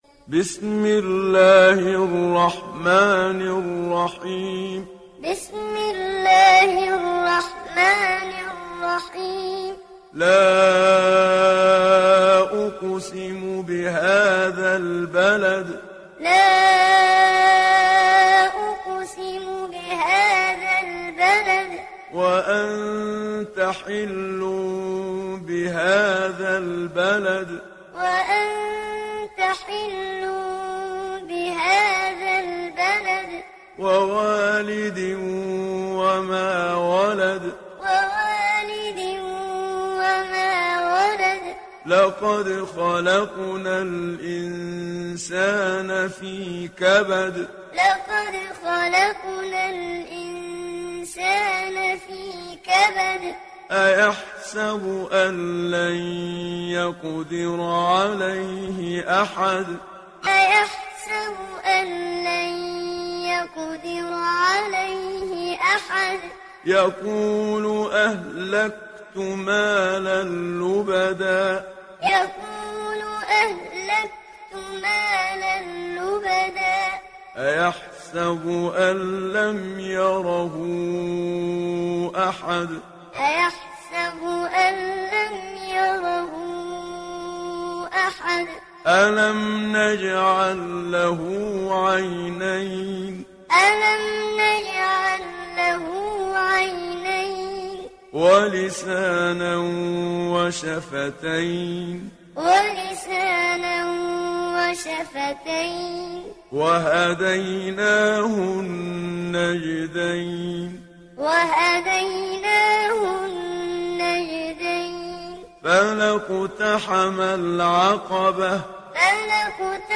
Surah Al Balad mp3 Download Muhammad Siddiq Minshawi Muallim (Riwayat Hafs)